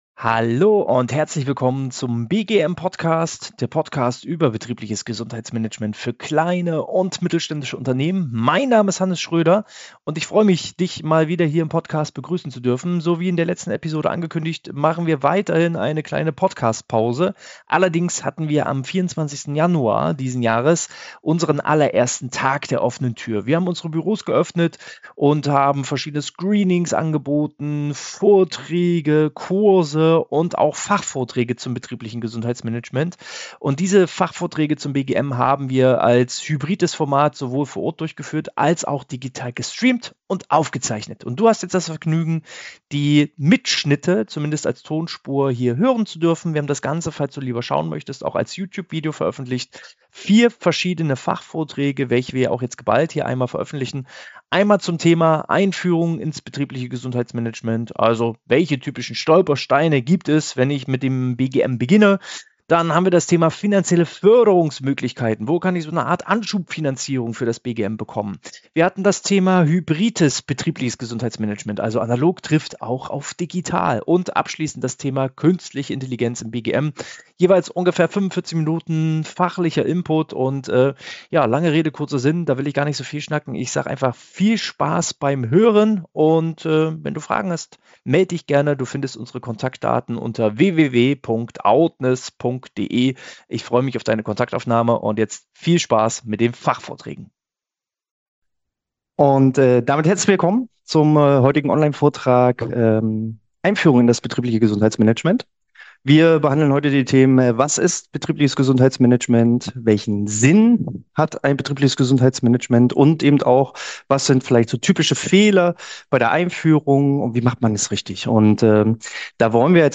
In diesem Fachvortrag erhältst Du eine kompakte Einführung in die Grundlagen des BGMs. Thematisiert werden Ziele, zentrale Bausteine sowie der konkrete Nutzen für Unternehmen und Mitarbeitende.